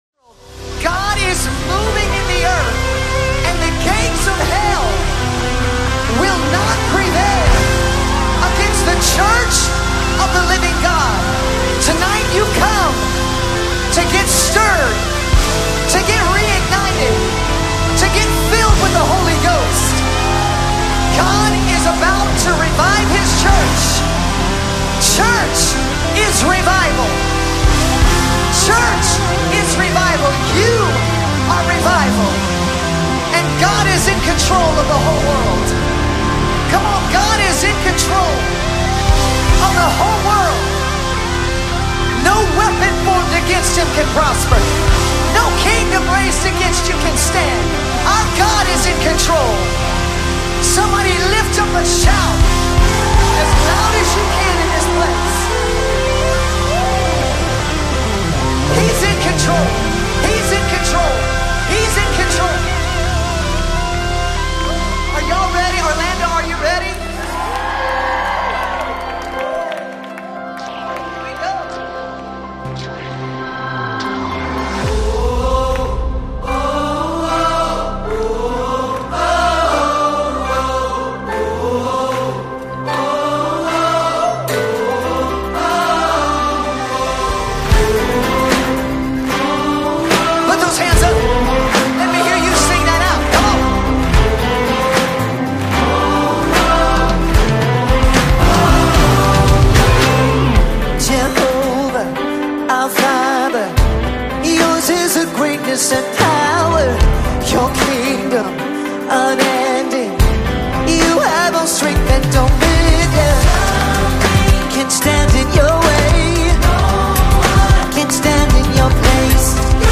American Gospel Songs